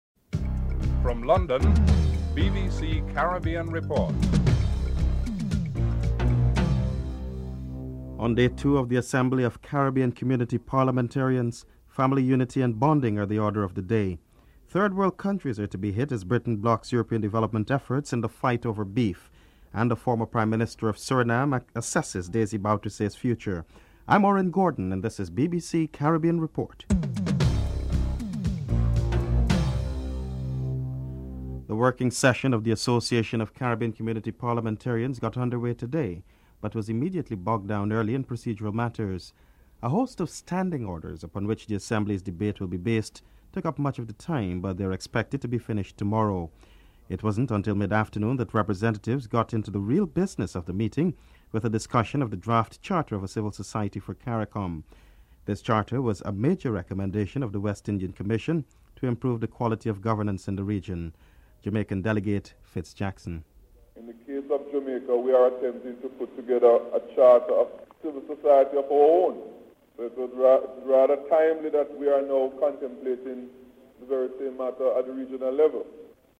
1. Headlines (00:00-00:30)
2. On day two of the Assembly of Caribbean Community Parliamentarians family unity and bonding are the order of the day. Jamaican Delegate Fitz Jackson, Trinidad and Tobago Delegate Camille Robinson-Regis and President Cheddi Jagan are interviewed (00:31-05:27)
3. Third World countries are to be hit as Britain blocks European Development efforts in the fight over beef. British Member of Parliament Bernie Grant, Overseas Aid Minister Linda Choka and European Commissioner Emma Bonino are interviewed (05:28-08:29)
Former Prime Minister Errol Alibux is interviewed (08:30-11:55)